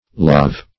lav \lav\ n. [by truncation of lavatory.]